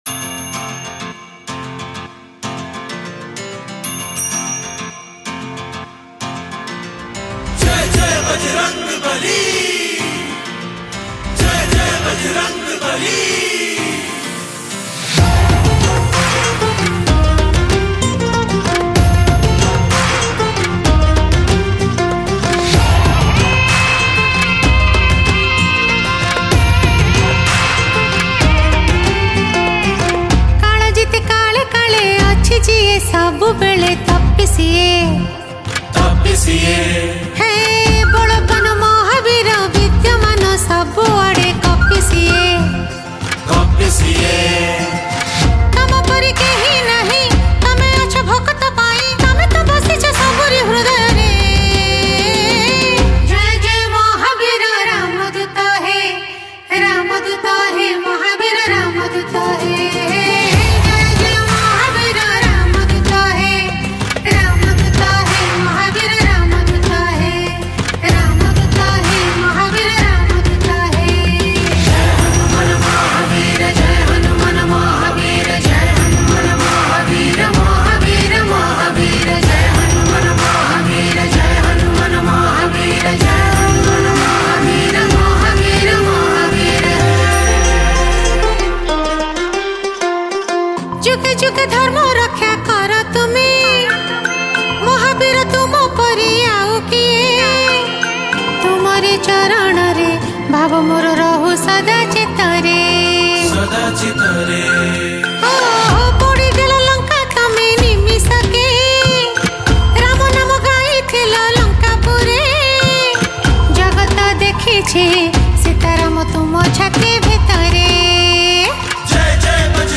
Pana Sankarati Special Bhajan